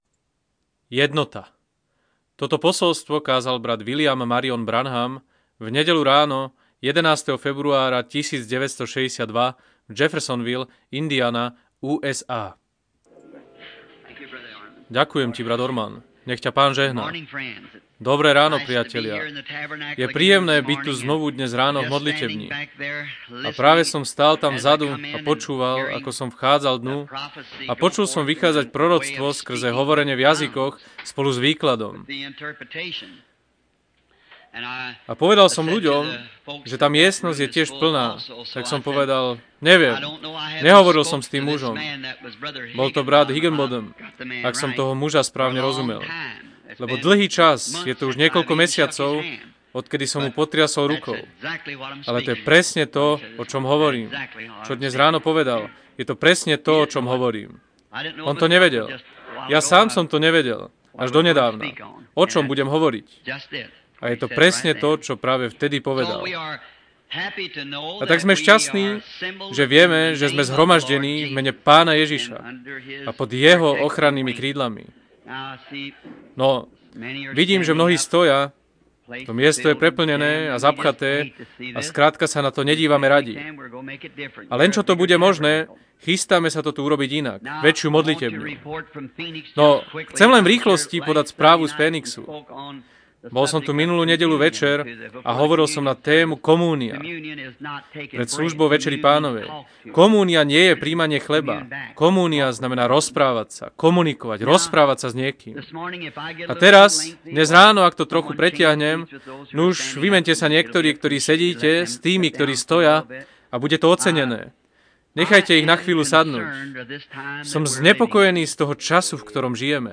Audio kázne